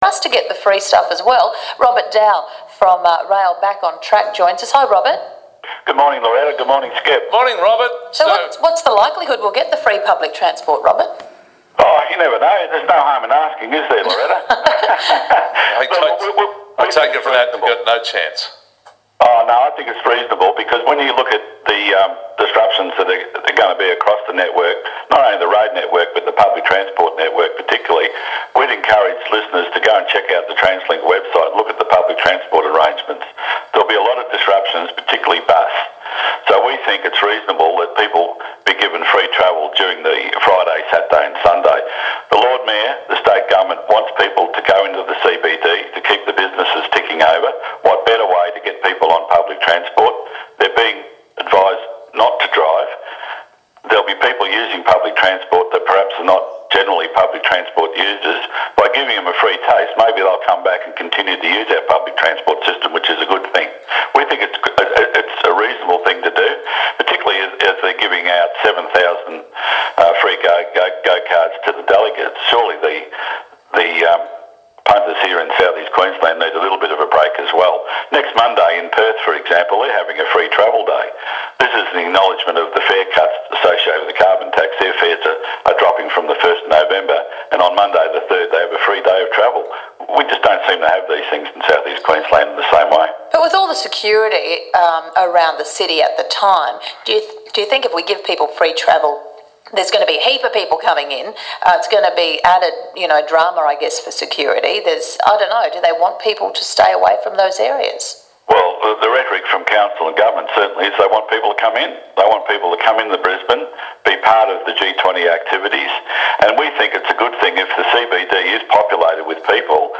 News Talk 4BC 1116 interview on Breakfast re free fares for G20